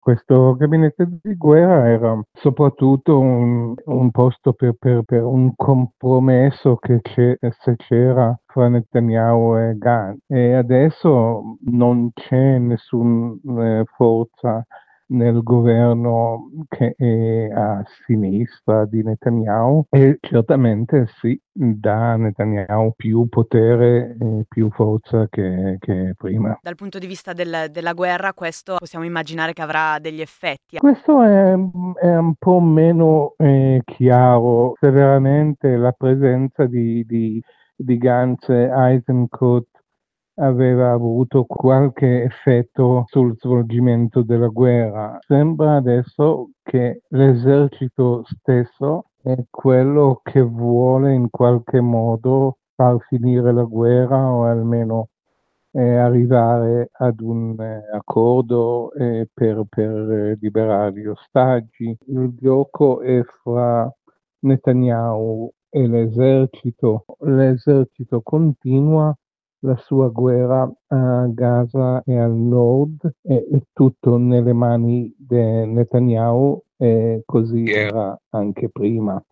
Il racconto della giornata di lunedì 17 giugno 2024 con le notizie principali del giornale radio delle 19.30. Nel Mediterraneo due naufragi nel giro di poche ore riportano l’attenzione su una strage che non ha mai fine; mentre a Gaza non si vede traccia della paventata “pausa tattica”, Netanyahu scioglie il gabinetto di guerra e aumenta così il potere del governo di ultradestra; la maggioranza popolari-socialisti-liberali prova a costruire la nuova Commissione Europea; le logiche securitarie e punitive del governo italiano generano affollamenti nelle carceri che nei casi più esasperati si traducono in suicidi.